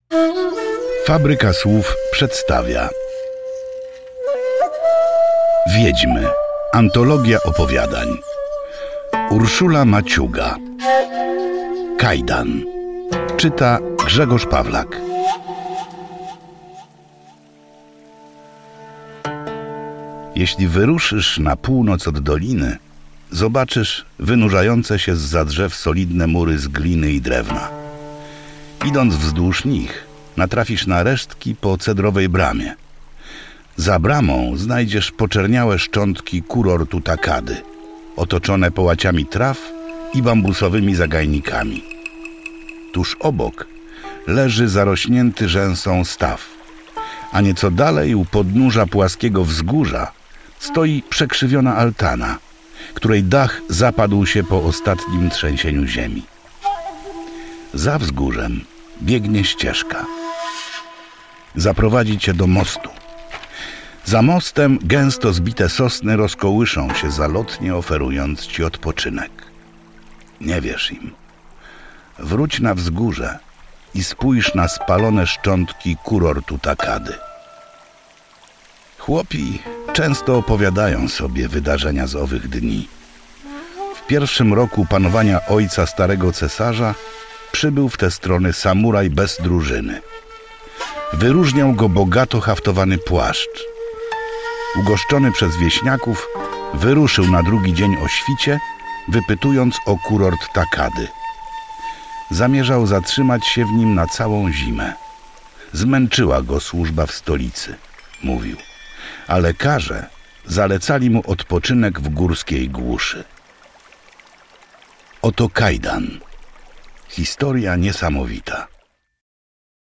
Wiedźmy - audiobook